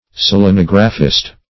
Selenographist \Sel`e*nog"ra*phist\, n. A selenographer.